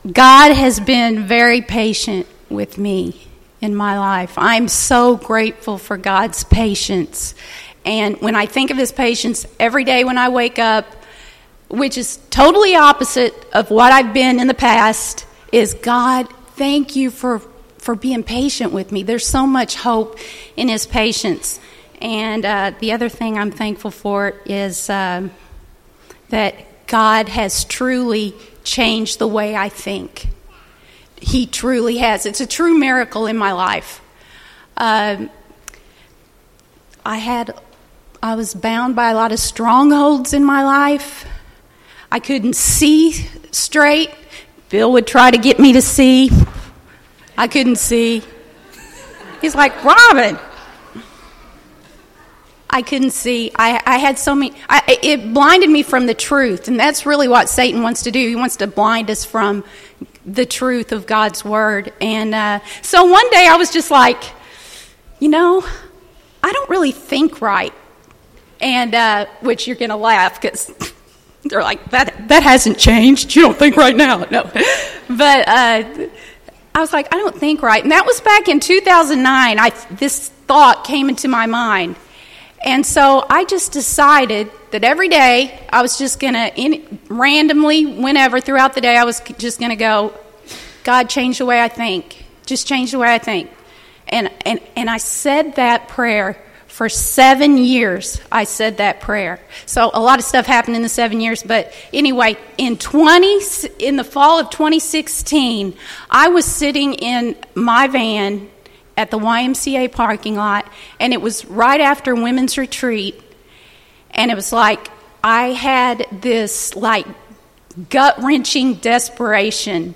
Personal Testimonies